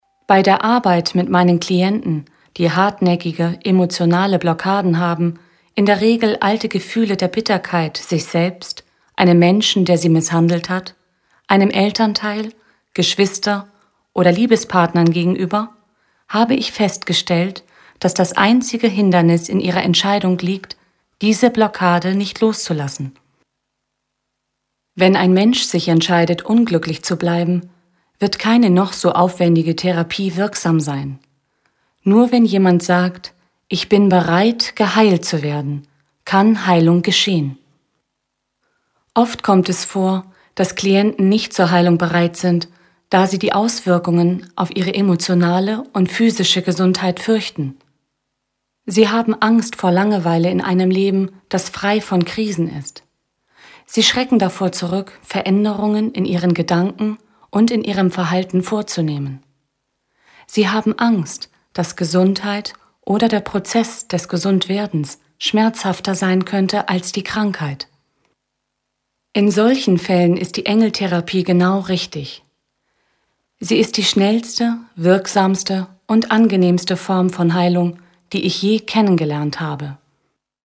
Diese Sonderedition ist eine meditative Ergänzung zu ihren Büchern und bringt jeden Hörer in den direkten Kontakt mit den himmlischen Helfern.